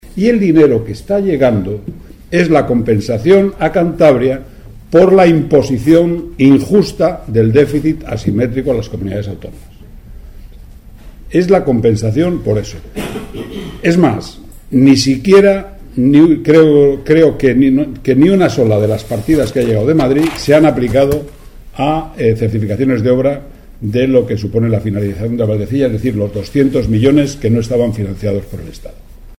Miguel ?ngel Revilla con Rafael de la Sierra y Jos? Mar?a Maz?n en la rueda de prensa de hoy